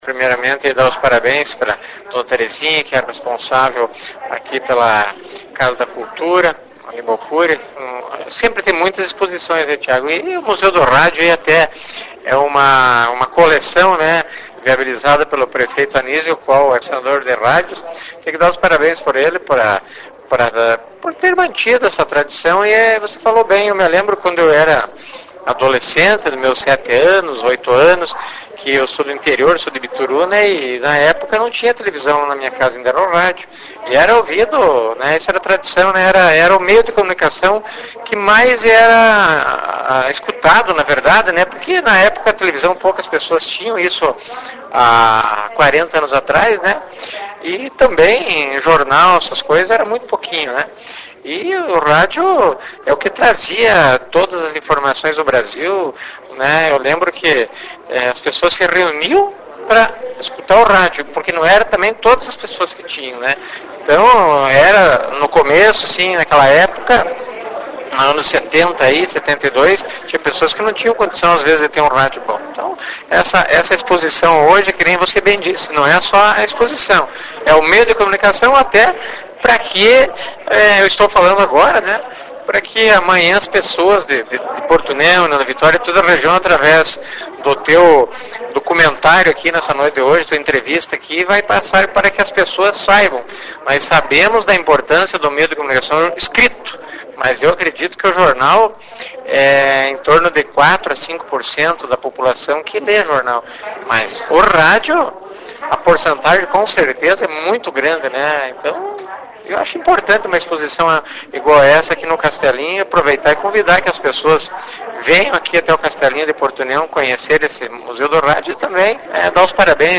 Como está reportagem fala de um dos veículos mais importantes da comunicação da sociedade, nada melhor do que as entrevistas serem no formato que muitas pessoas estão sempre acostumadas a ouvir nas ondas de Amplitude Modulada (AM) ou Frequência Modulada (FM).
Outro convidado que se lembrou da história do rádio, foi o vice-prefeito de Porto União Aloísio Salvatti, que é natural da cidade de Bituruna (PR), e recordou como o rádio chegou ao interior e as famílias se união para ouvir as radionovela e o Repórter Esso.